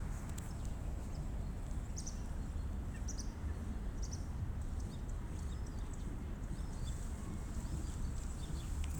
Baltā cielava, Motacilla alba
Ziņotāja saglabāts vietas nosaukumsKronvalda parks
StatussDzirdēta balss, saucieni